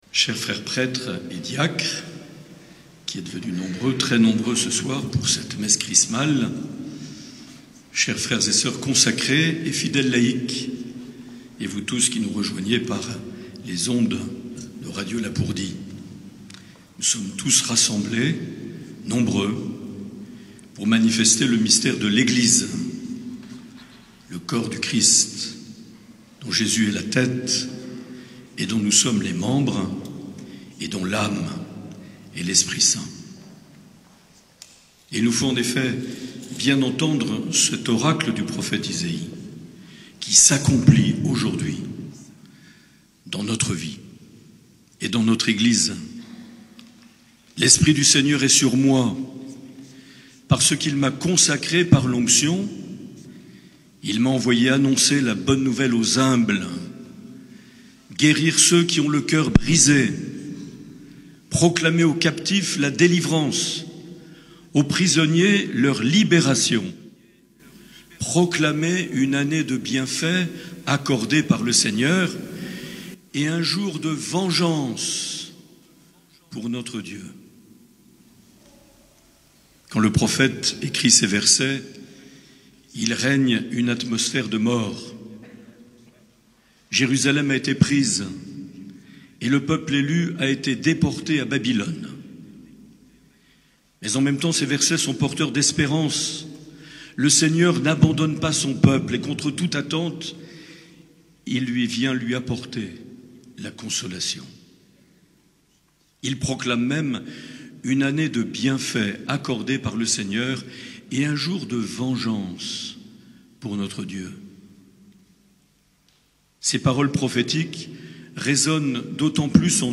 12 avril 2022 - Cathédrale de Lescar - Messe Chrismale
Les Homélies
Une émission présentée par Monseigneur Marc Aillet